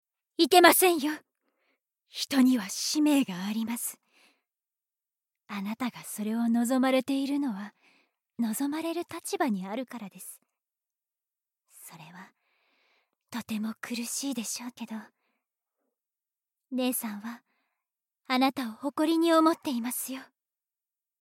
ボイスサンプル
名家の女性